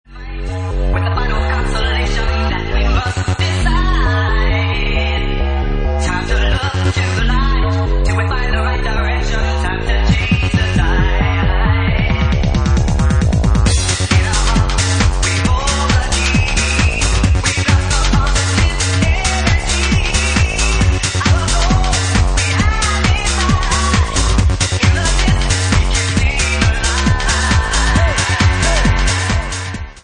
Genre:Bassline House
Bassline House at 135 bpm